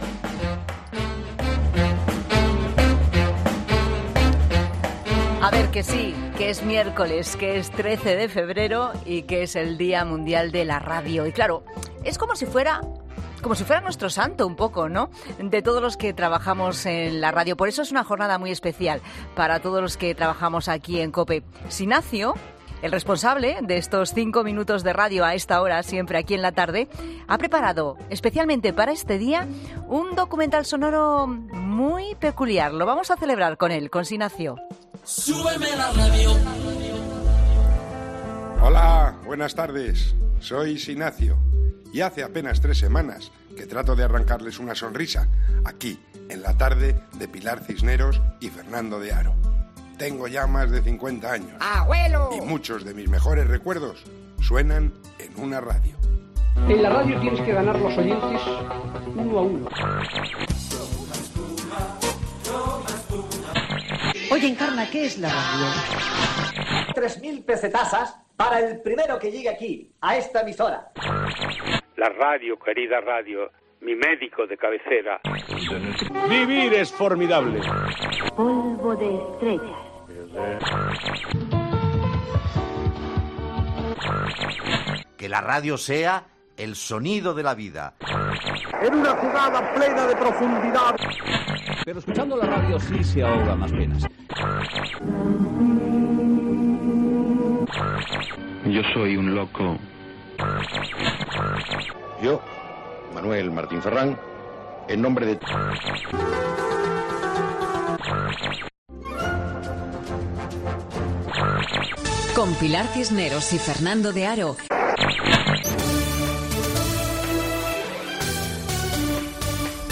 Prepárense para un viaje por el dial de nuestra memoria, recuperando las voces y los sonidos de nuestras vidas